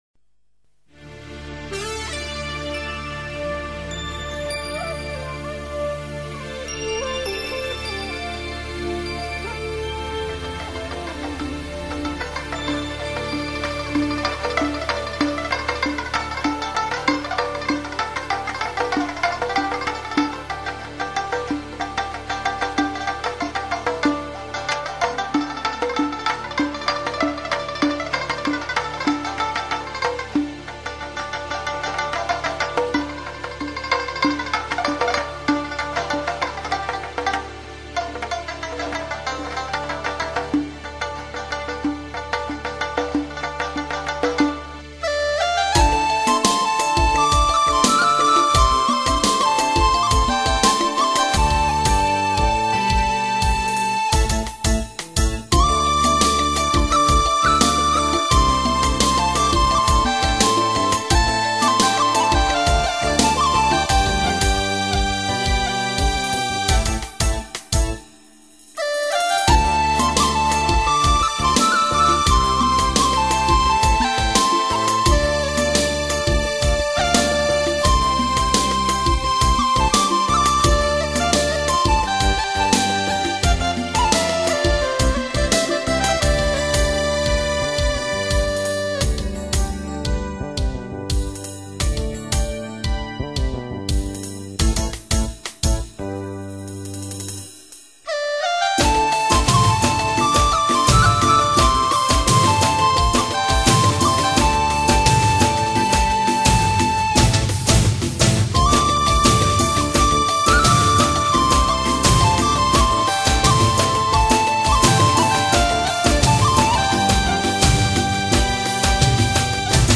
新疆民族特色乐器演奏3CD之(1)
热瓦甫独奏